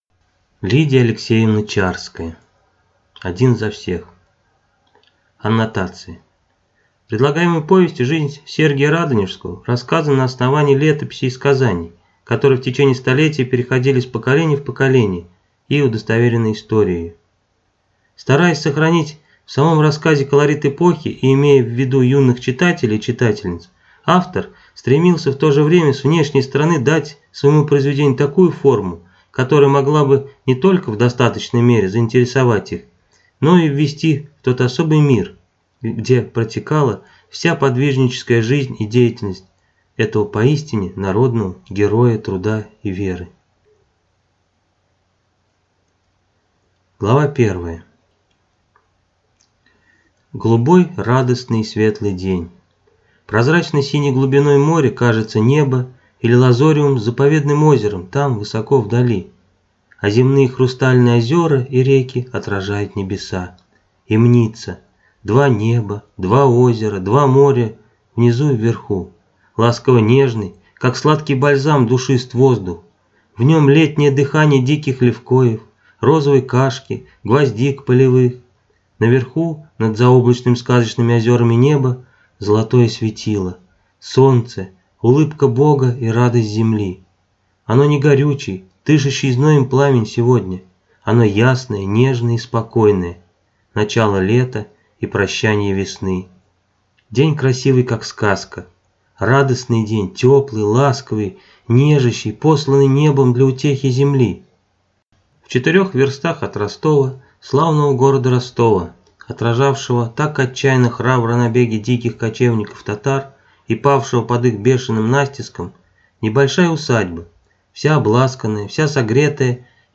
Аудиокнига Один за всех | Библиотека аудиокниг
Прослушать и бесплатно скачать фрагмент аудиокниги